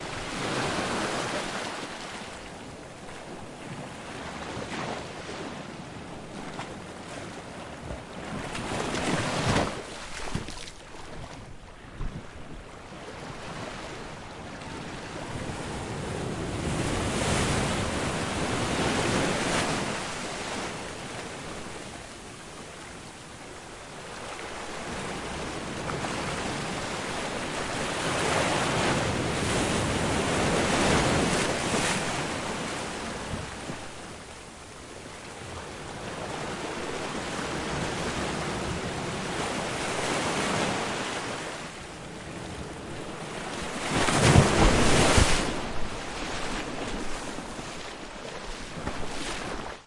大不列颠 " 岩石内的波浪
我自己用ZOOM H4录制。
标签： 飞溅 沙滩 海洋 氛围 海浪 海边 飞溅 海岸 沙滩 海边 声景观 自然 现场录音 岩石 夏天
声道立体声